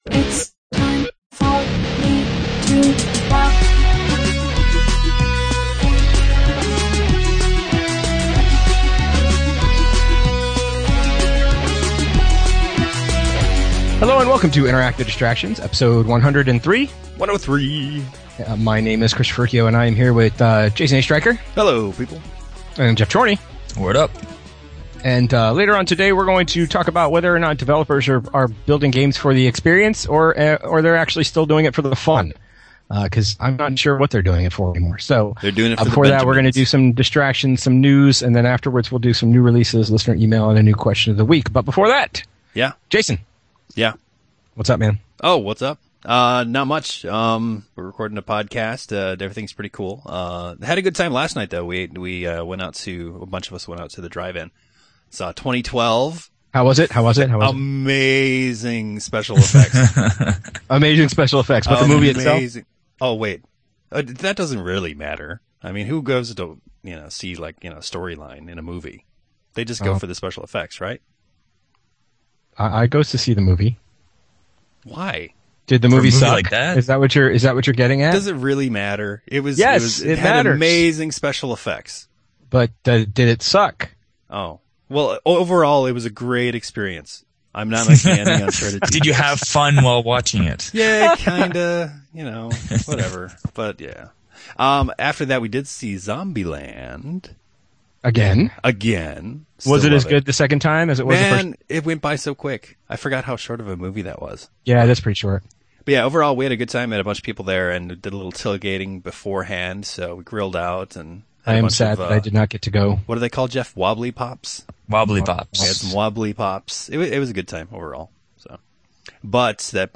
We recorded last night so, in order to get the show out to you guys quicker, we used the back up… so the audio quality is not quite where it usually is for us.